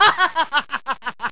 mlaugh.mp3